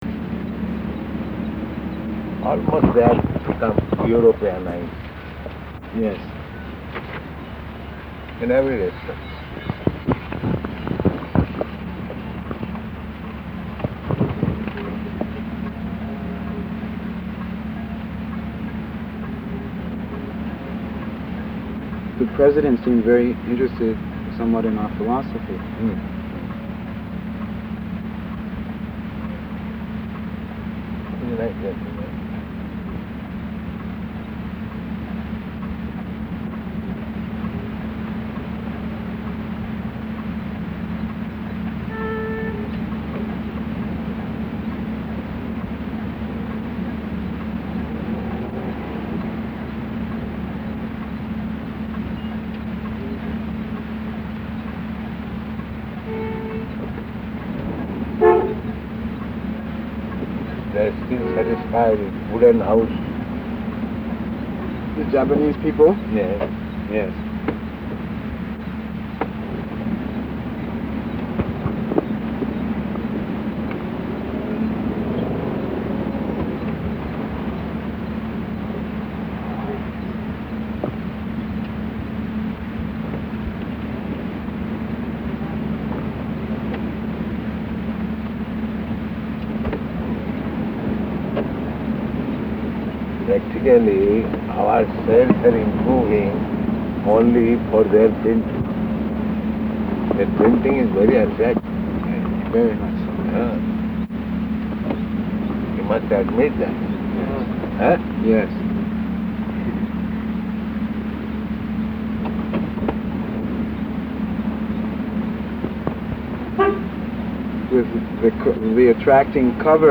Conversation in car
Location: Tokyo